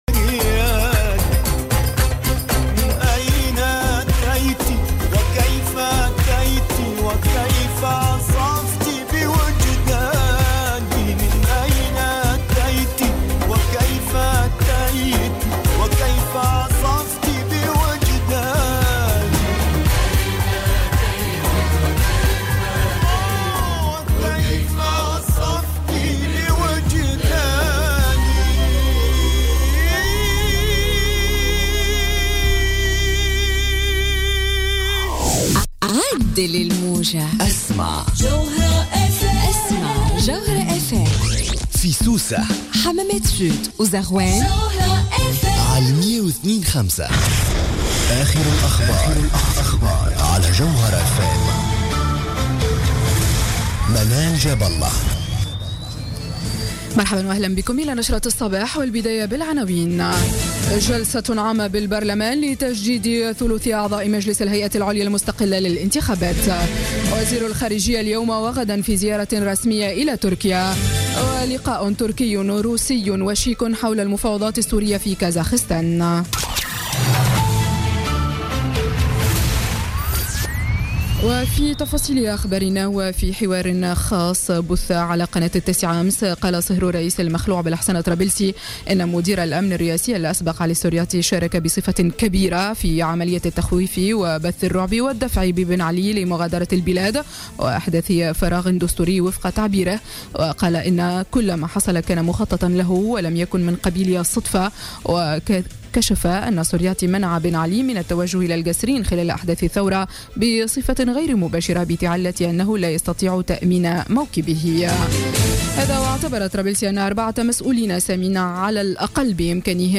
انطلقت الكاتبة ألفة يوسف اليوم في افتتاحيتها لـ "الجوهرة اف أم" من مؤشر يتعلق بتوجه سياسة ترامب نحو منع أبرز مصانع الولايات المتحدة من الاستثمار خارج الولايات المتحدة.